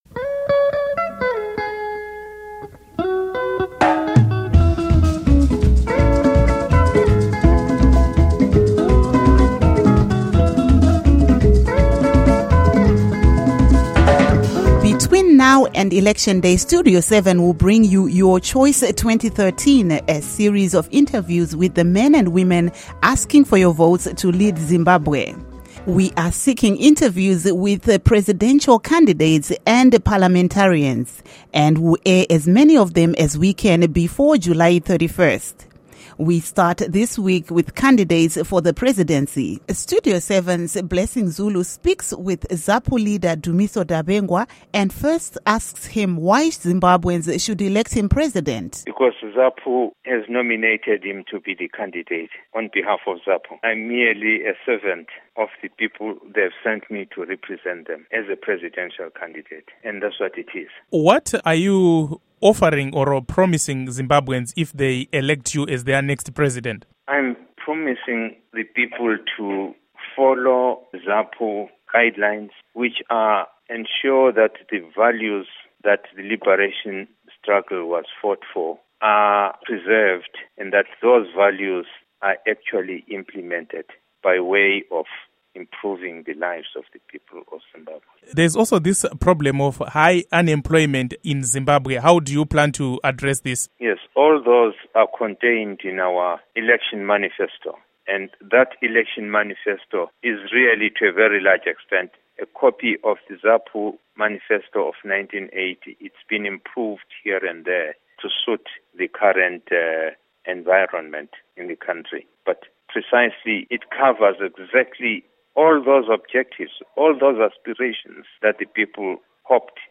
Interview With Dumiso Dabengwa